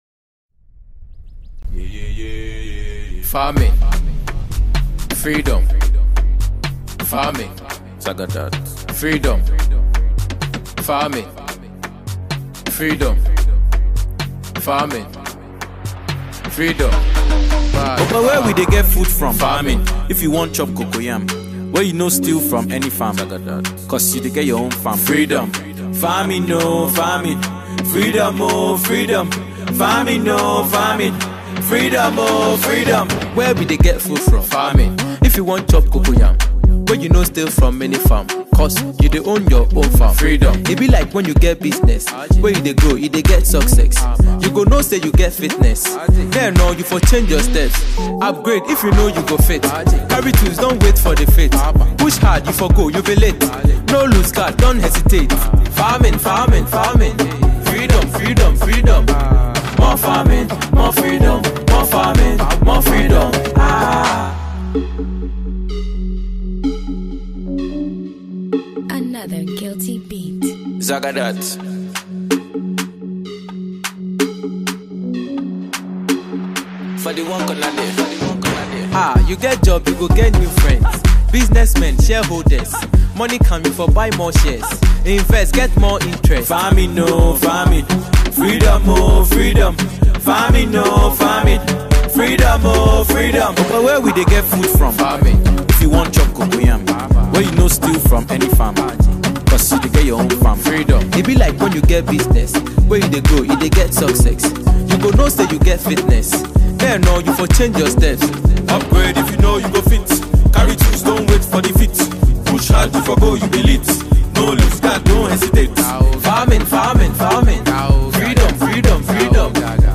dance anthem